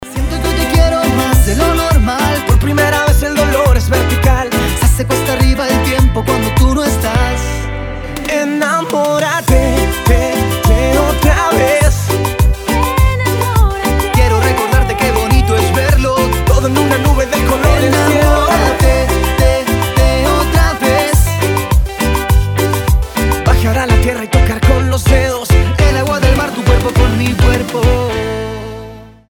• Качество: 320, Stereo
зажигательные
заводные
дуэт
Latin Pop
мужской и женский вокал